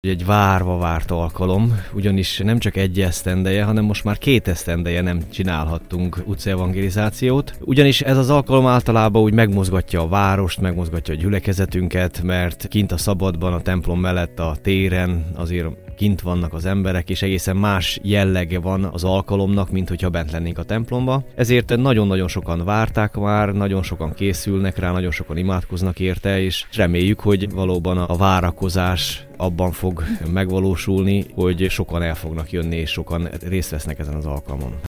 tarackozi_riport_hirekbe.mp3